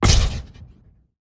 mag_ward_block_003.wav